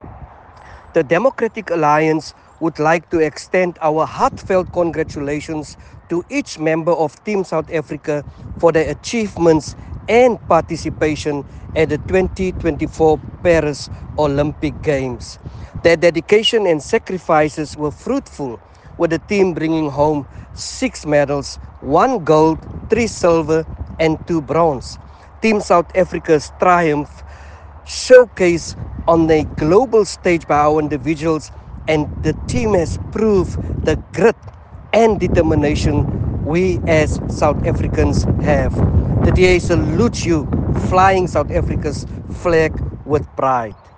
soundbite by Joe McGluwa MP